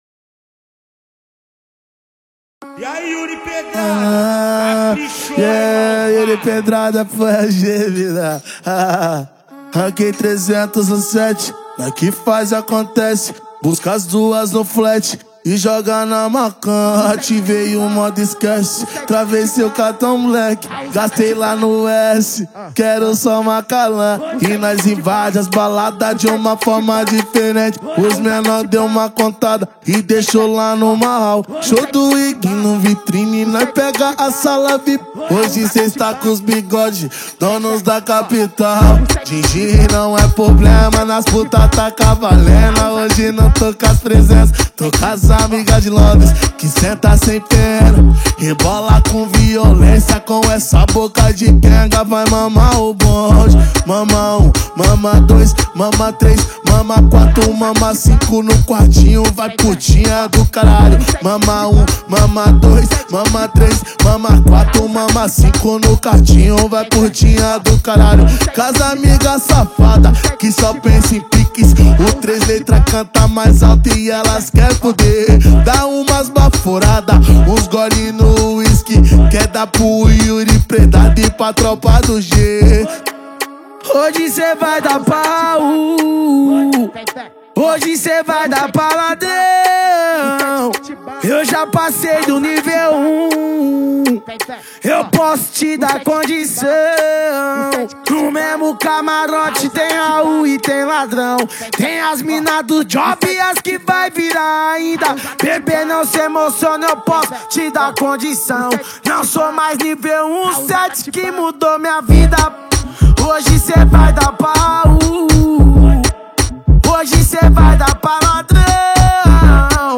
2024-11-09 18:52:50 Gênero: MPB Views